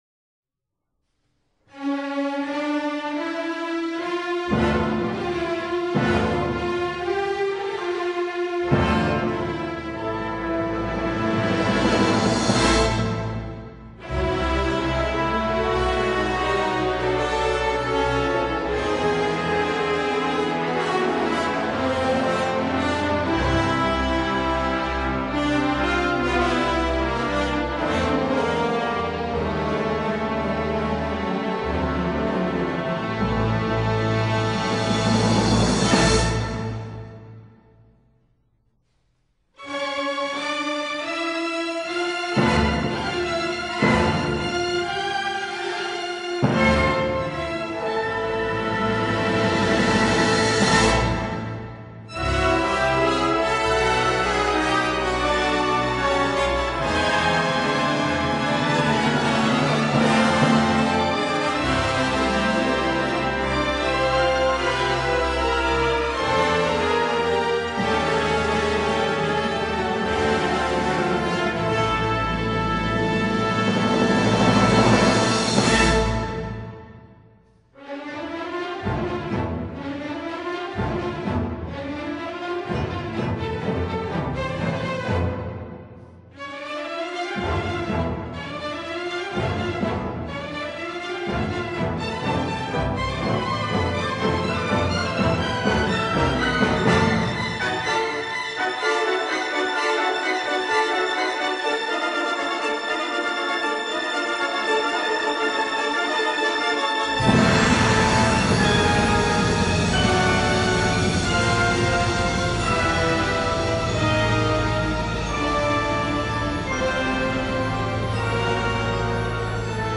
Rhapsody for Orchestra